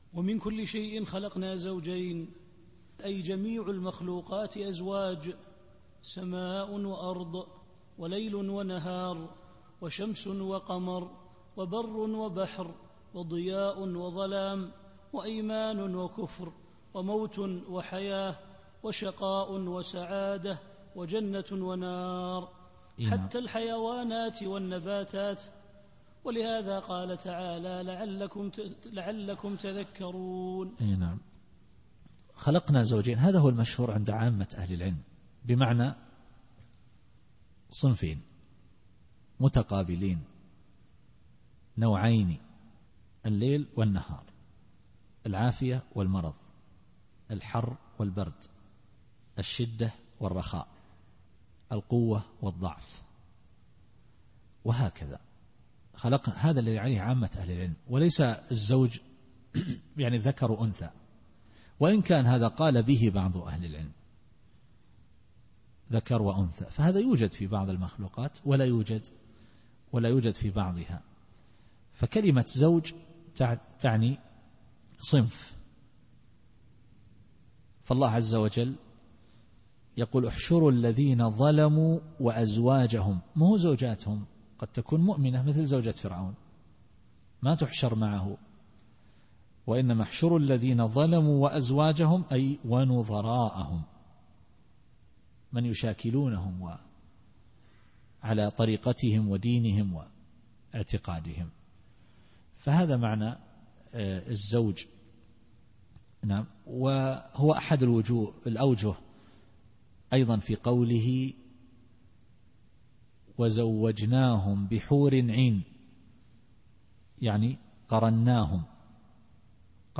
التفسير الصوتي [الذاريات / 49]